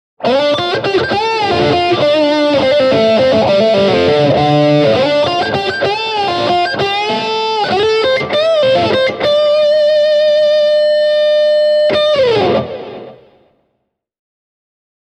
Tässä muutama esimerkkisoundi:
Blackstar ID:60TVP – Strat OD2 delay reverb